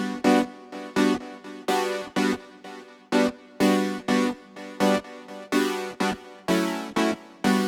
32 Synth PT2.wav